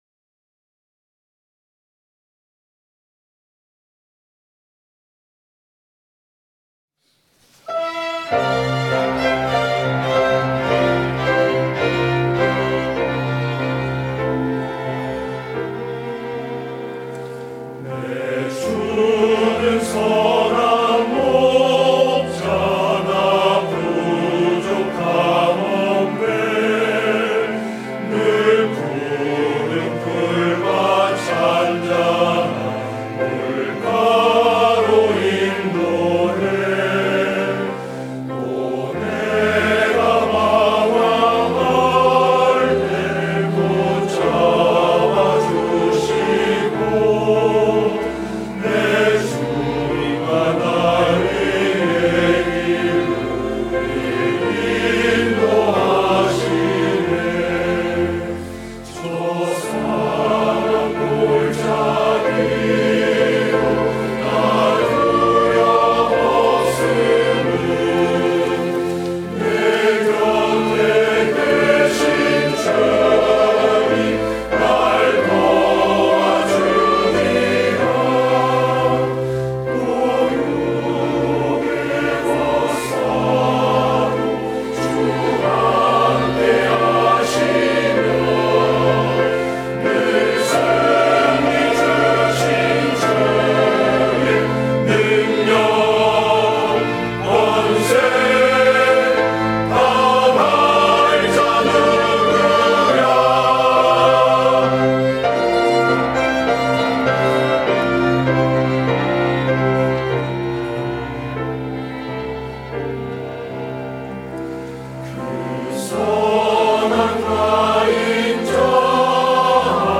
시온